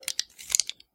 工具 剃刀片 In04
描述：正在处理盒式切割机的声音。 此文件已标准化，大部分背景噪音已删除。没有进行任何其他处理。
Tag: 切割机 缩回 刀片 工具 扩展 剃须刀